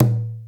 keys_36.wav